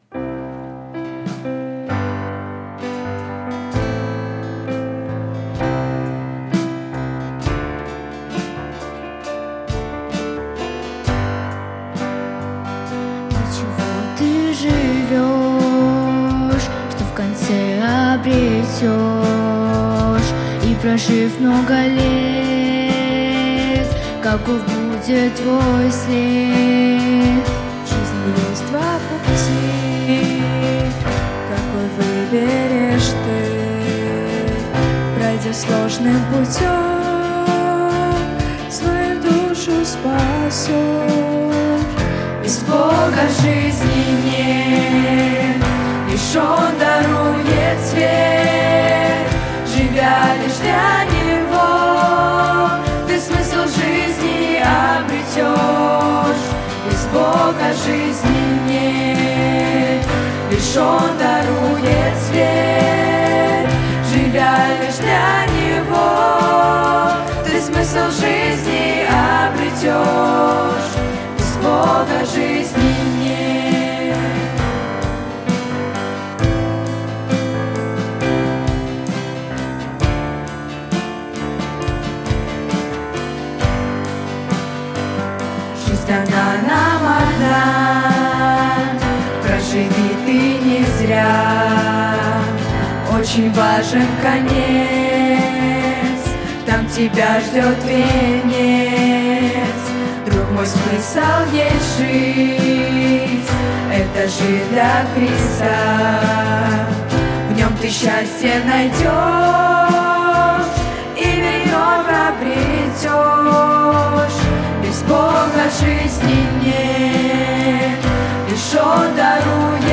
Музыкальная группа
вакал
гітара
клавішы
скрыпка
бас-гітара
ударныя
саксафон